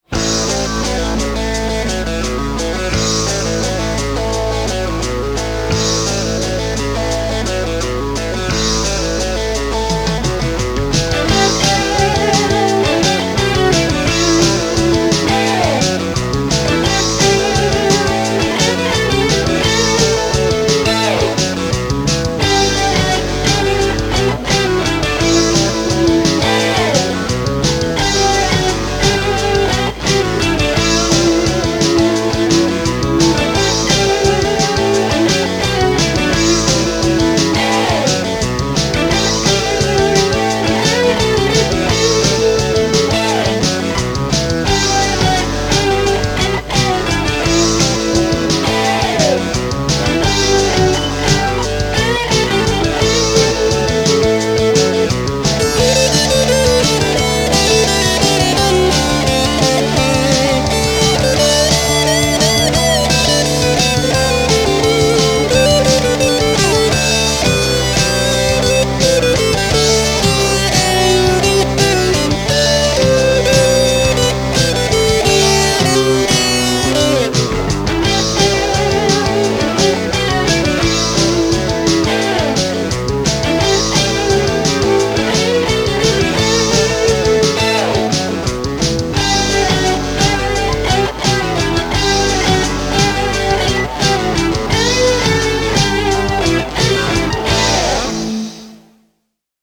Zwangsläufig ergibt sich auch dabei (trotz DBX-Rauschunterdrückung) ein deutliches Maß an Bandrauschen und Abmischfehlern.
Das Schlagzeug wurde durch "Electronic Drums" realisiert.
Bass, Schlagzeug, Gitarre, geschrieben für Straight On!